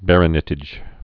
(bărə-nĭ-tĭj, -nĕtĭj)